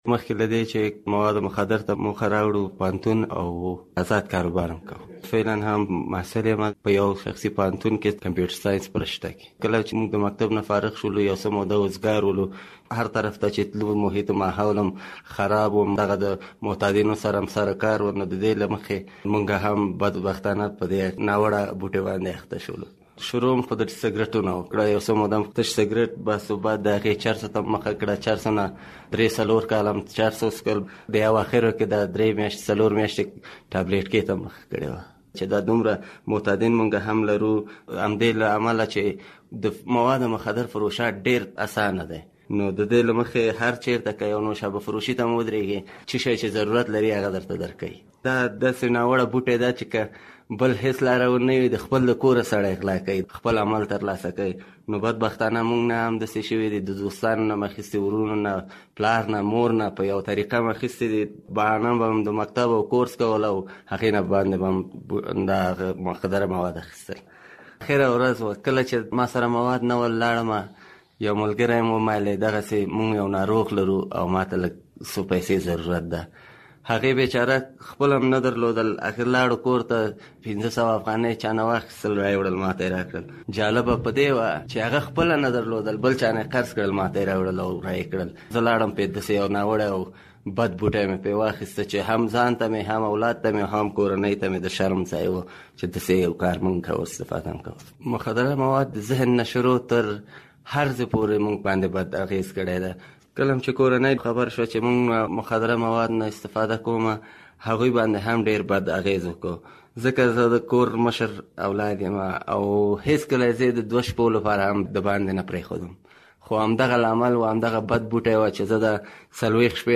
سره مرکه